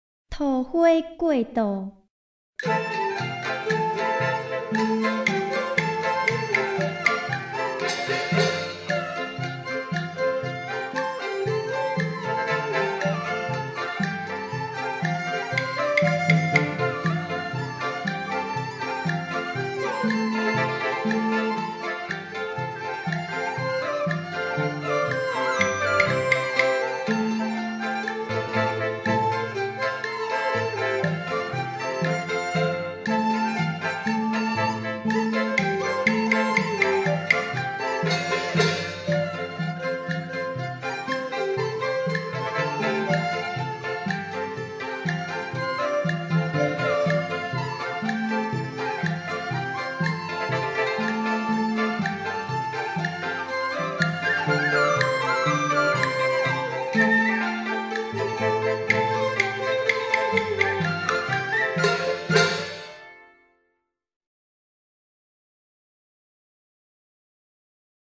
已錄製人聲(VocalA)檔案 音樂(MusicA)檔案 混音後檔案